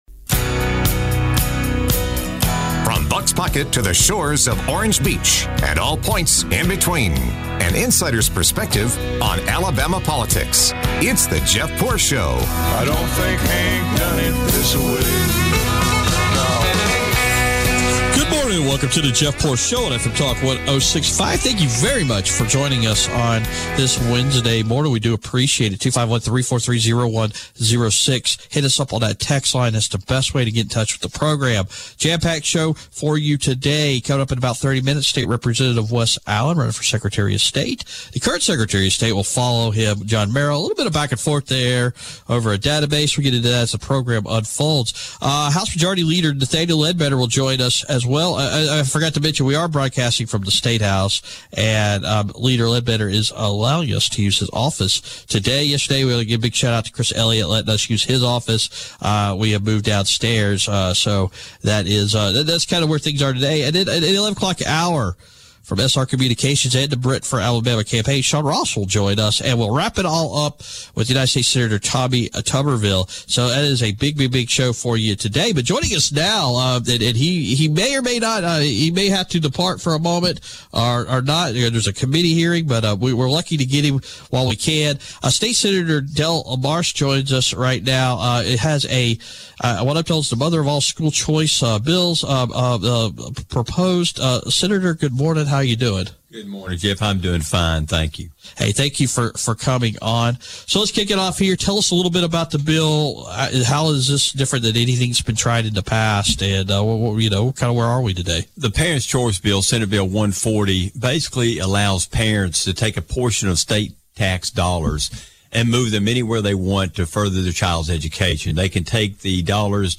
from the Alabama State House